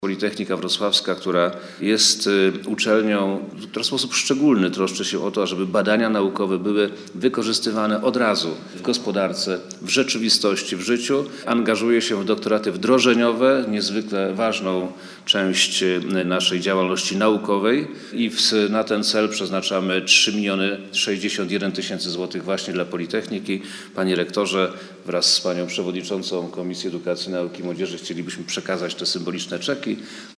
Dodatkowo Politechnika Wrocławska otrzyma ponad 3 mln złotych na program doktoratów wdrożeniowych, dodaje minister.